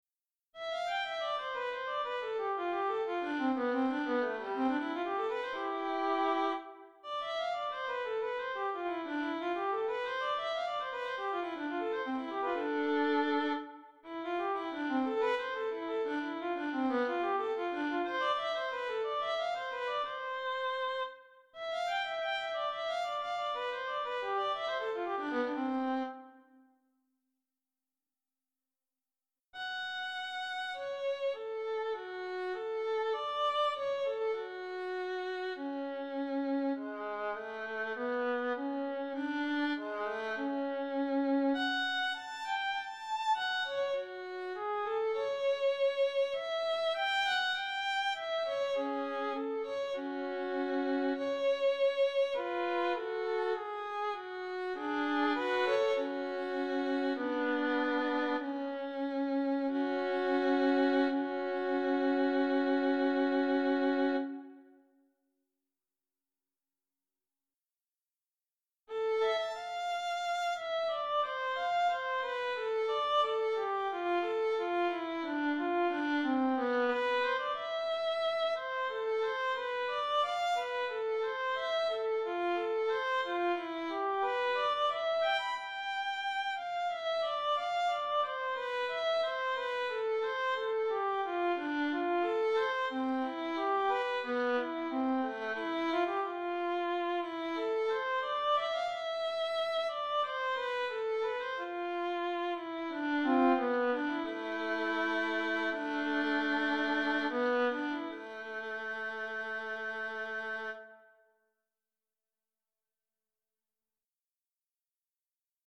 Three Sententiae for Violin Op. 308